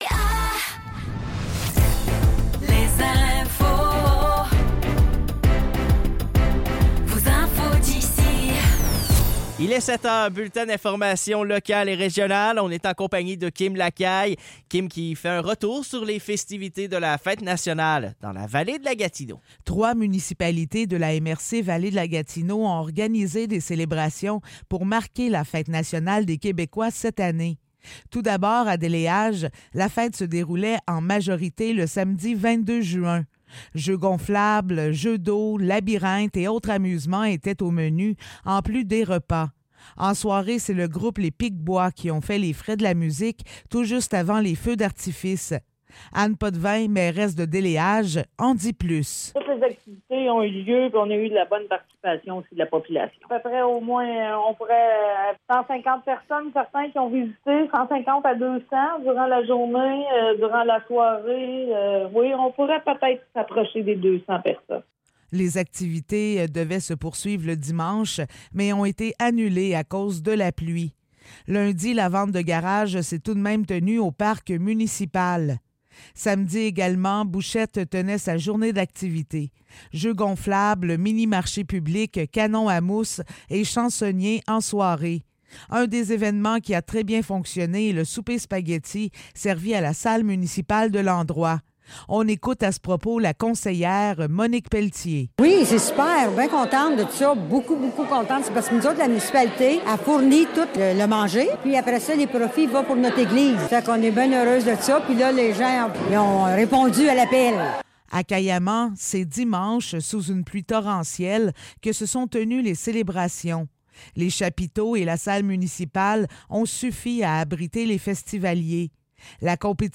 Nouvelles locales - 25 juin 2024 - 7 h